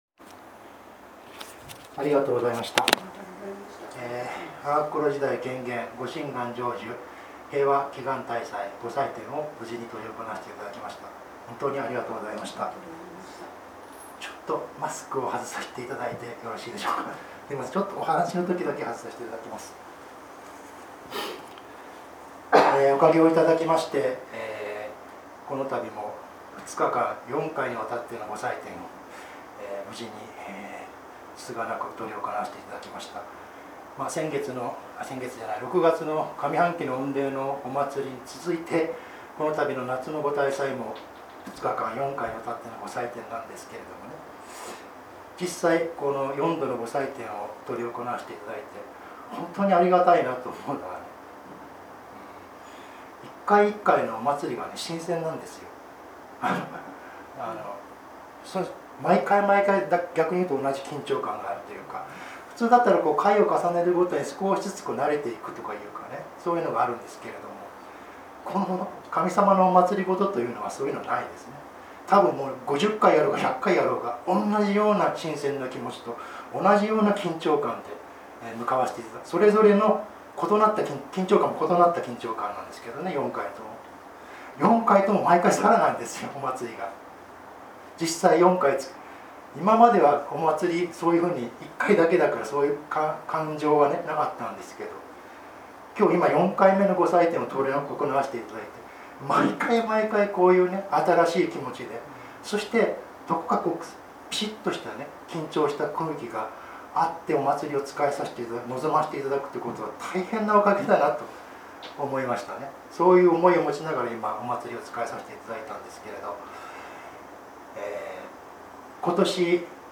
平和祈願大祭教話